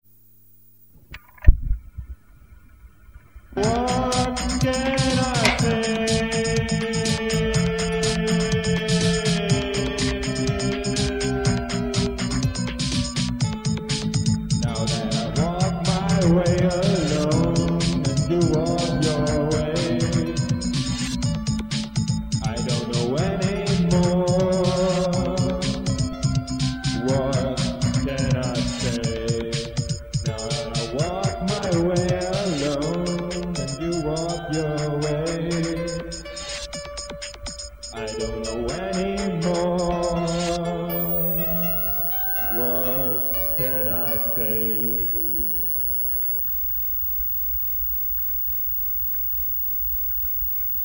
Sozusagen ein Outtake von meinen Probeaufnahmen.